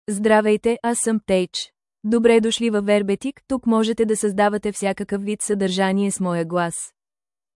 BethFemale Bulgarian AI voice
Beth is a female AI voice for Bulgarian (Bulgaria).
Voice sample
Female
Beth delivers clear pronunciation with authentic Bulgaria Bulgarian intonation, making your content sound professionally produced.